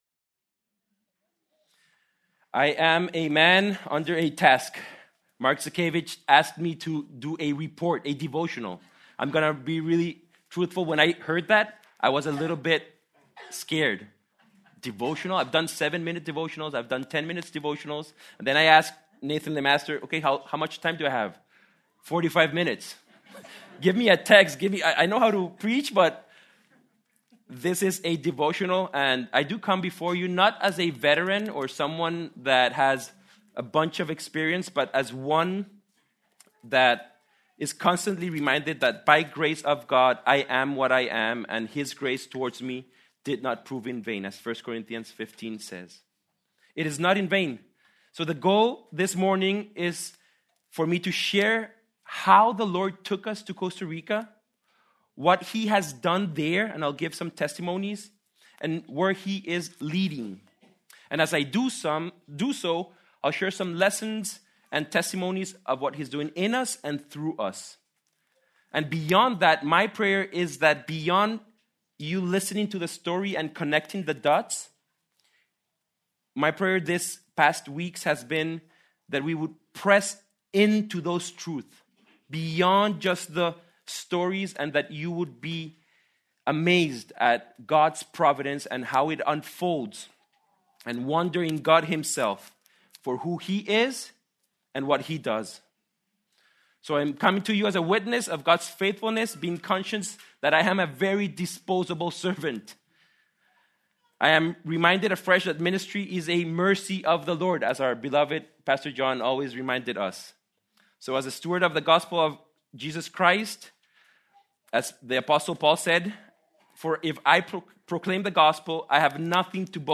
March 8, 2026 - Sermon